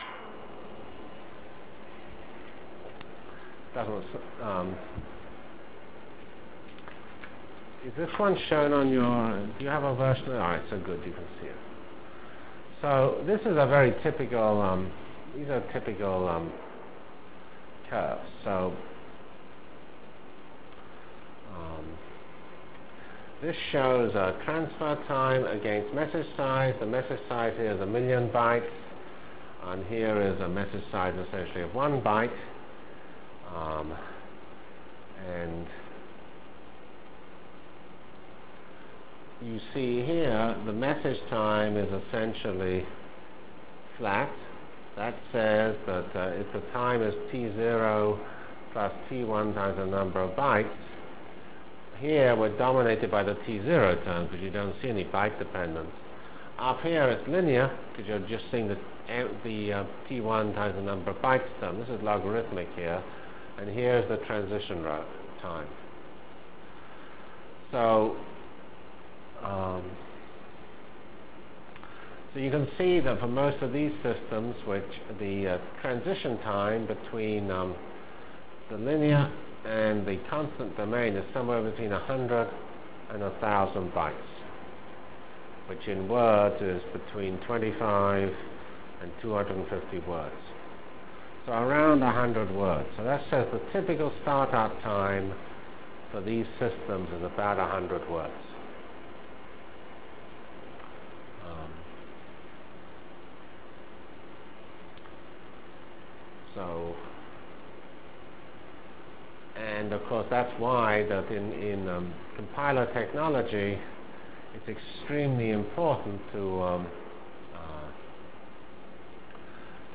From CPS615-Lecture on Computer Architectures and Networks Delivered Lectures of CPS615 Basic Simulation Track for Computational Science -- 12 September 96.